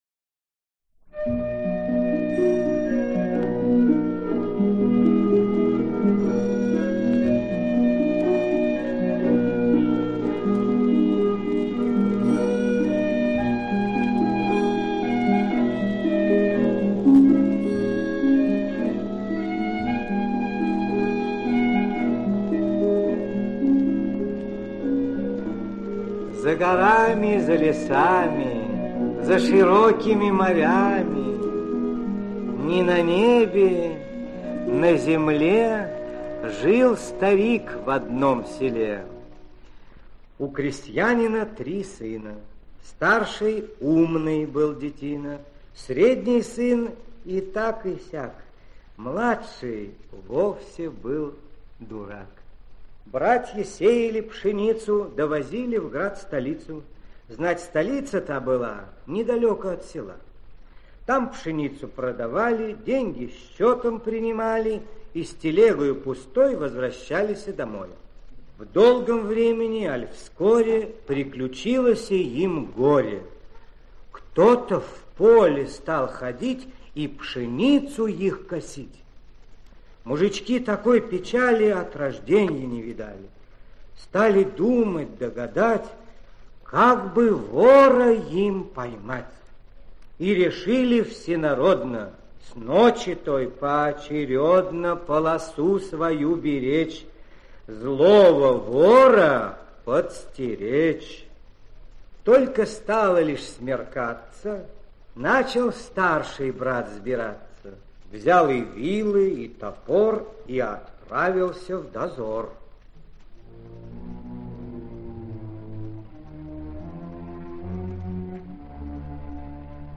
Аудиокнига Конек-горбунок | Библиотека аудиокниг